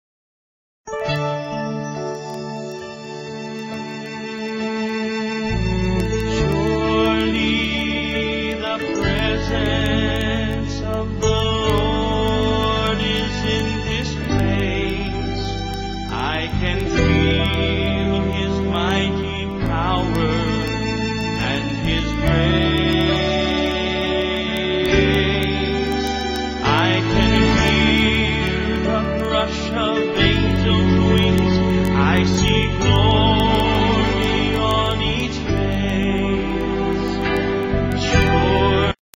4 tracks WITH BACKGROUND VOCALS